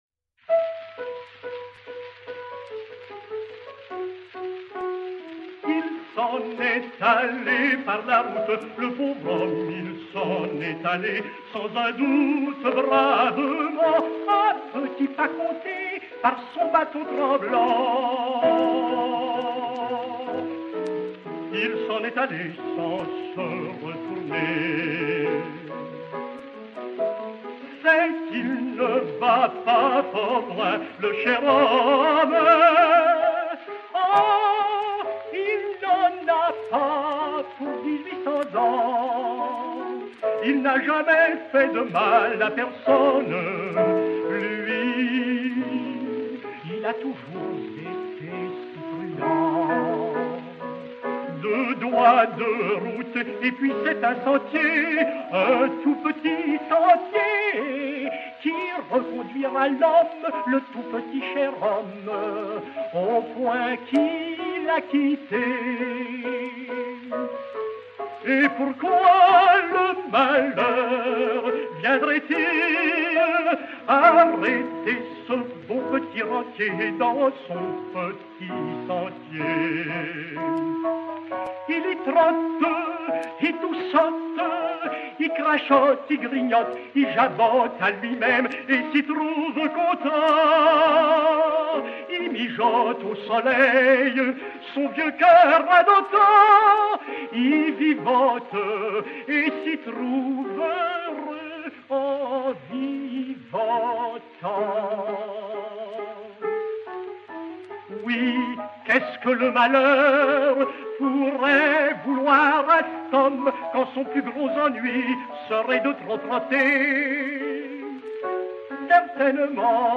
Ballade (par.
piano